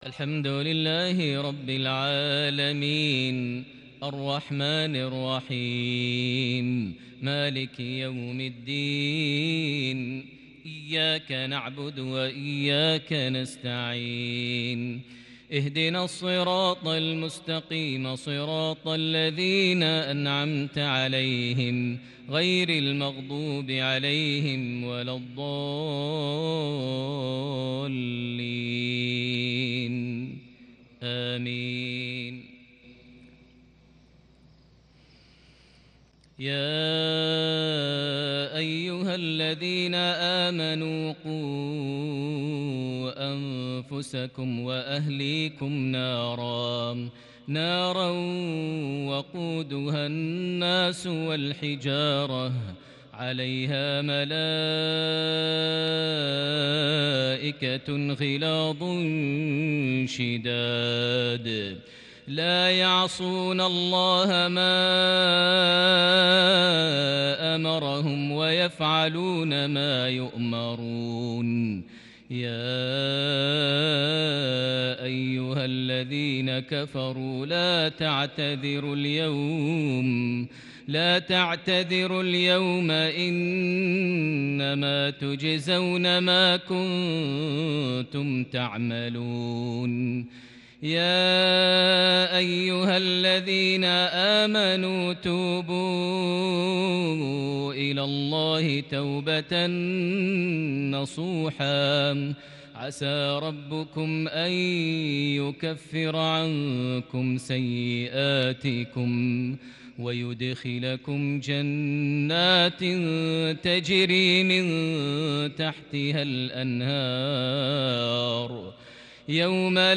تلاوة مباركه من سورة التحريم (6-12) | مغرب 23 صفر 1442هـ > 1442 هـ > الفروض - تلاوات ماهر المعيقلي